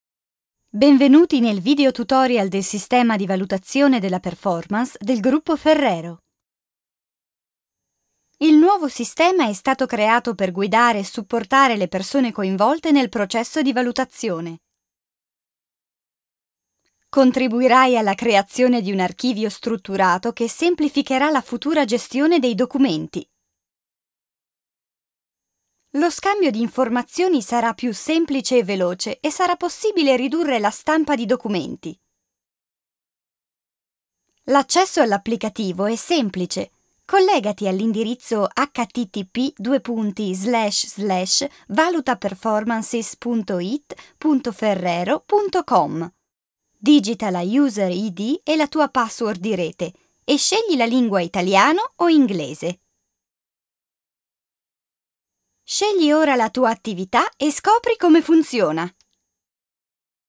Kein Dialekt
Sprechprobe: eLearning (Muttersprache):
I am a speaker and dubber from Milan with a fresh, professional, extremely versatile kind of voice and, of course, perfect diction.